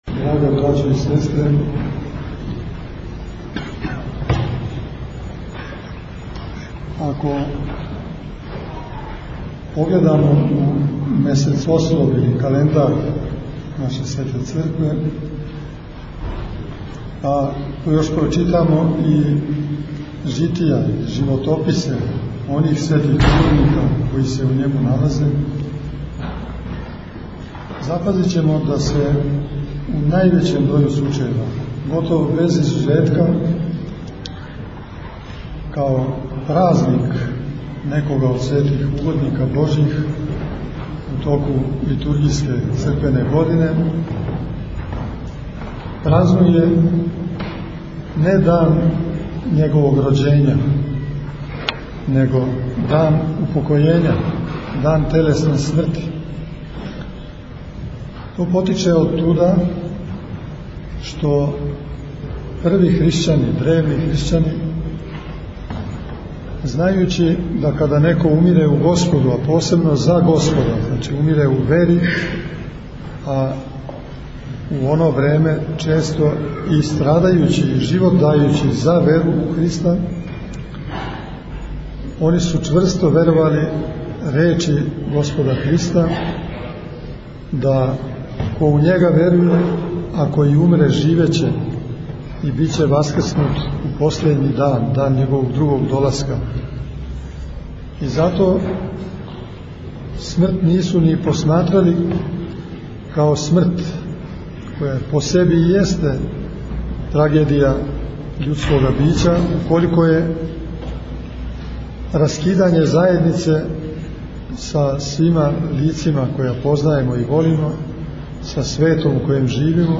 У својој беседи Владика је поучио верни народ о светитељу који се прославља и његовом месту у домостроју спасења човечијег.